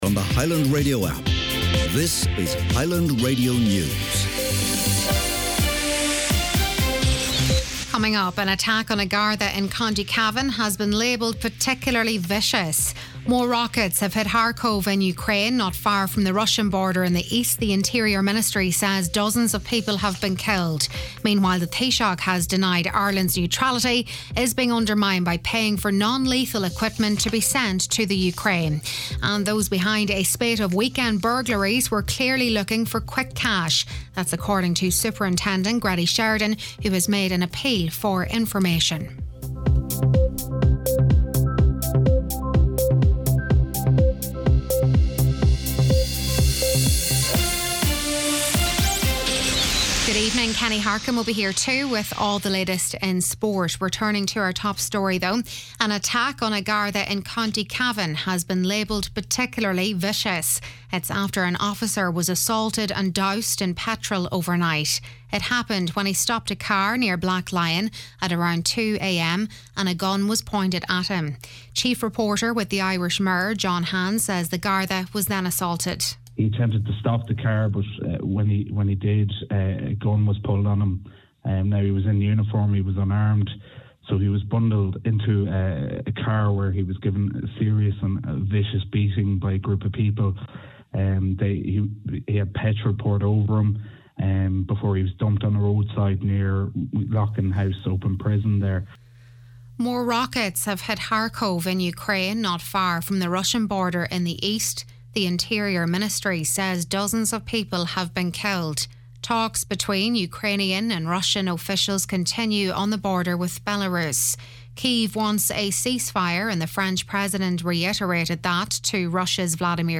Listen back to main evening news, sport & obituaries